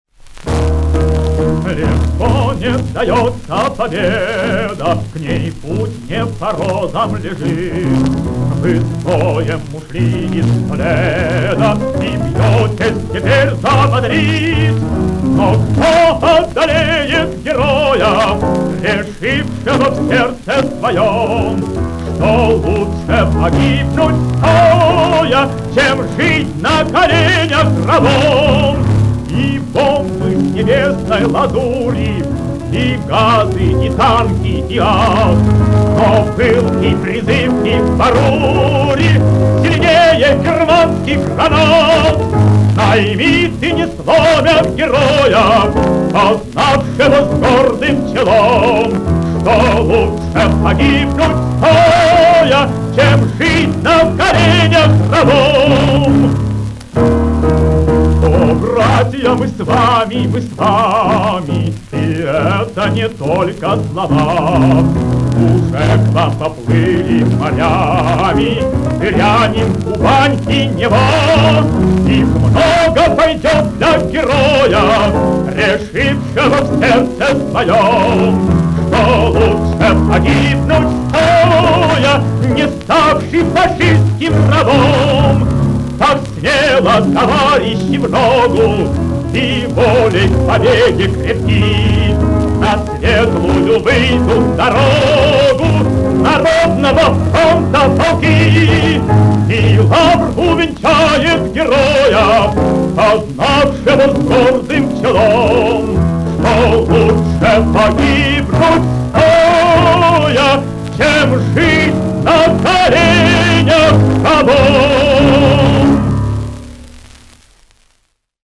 ф-но